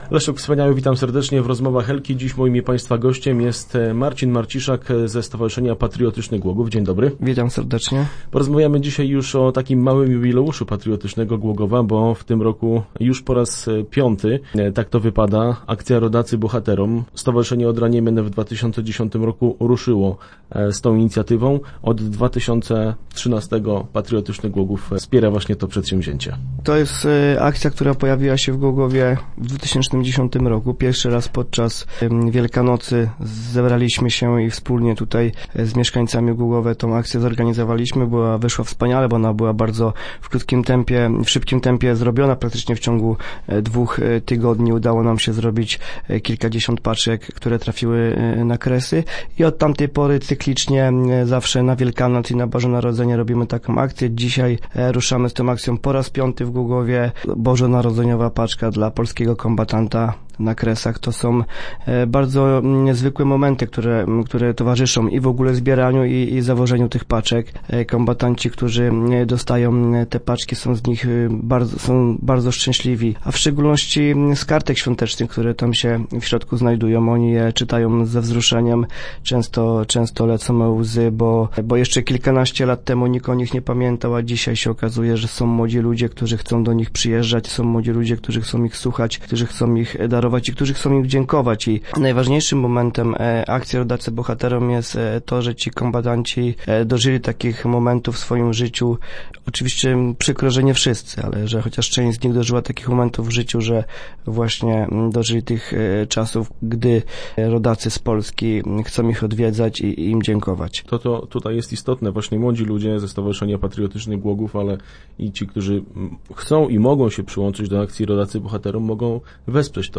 Start arrow Rozmowy Elki arrow Liczy się każdy produkt